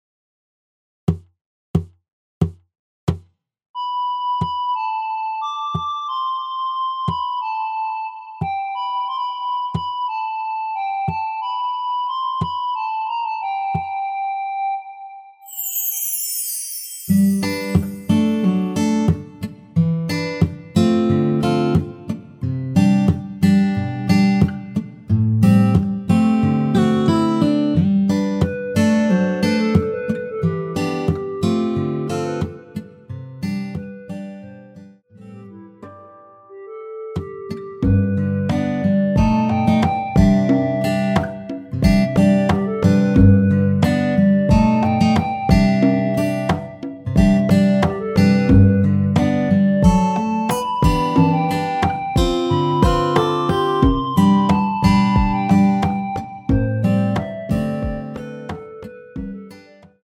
노래 들어가기 쉽게 전주 1마디 넣었으며
노래 시작 앞부분이 무반주라서 기타 바디 어택으로
원키에서(+3)올린 멜로디 포함된 MR입니다.
앞부분30초, 뒷부분30초씩 편집해서 올려 드리고 있습니다.
중간에 음이 끈어지고 다시 나오는 이유는
(멜로디 MR)은 가이드 멜로디가 포함된 MR 입니다.